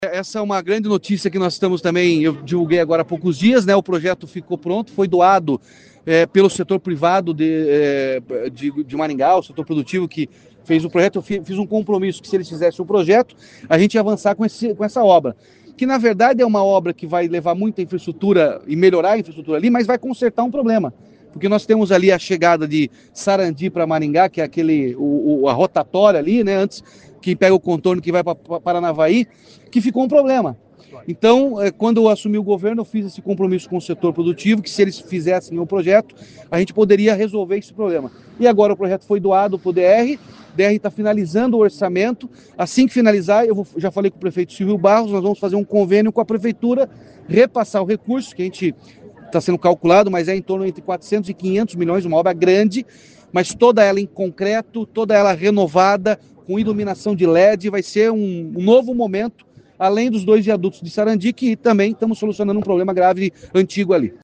O governador também falou sobre a duplicação do Contorno Sul de Maringá